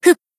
BA_V_CH0187_Battle_Shout_2.ogg